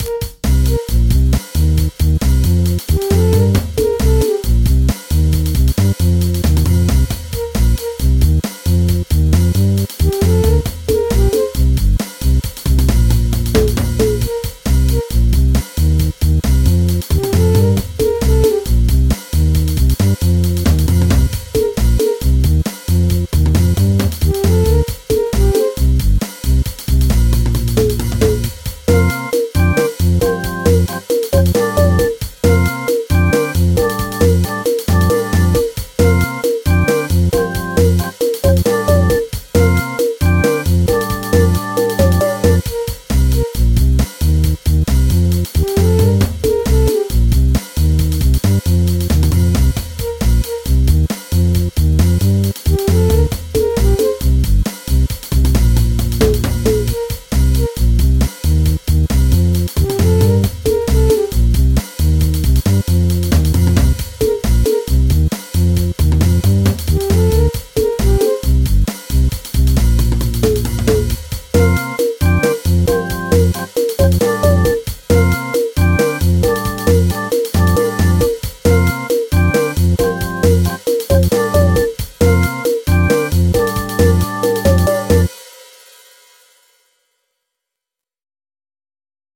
MIDI 11.59 KB MP3 (Converted) 1.36 MB MIDI-XML Sheet Music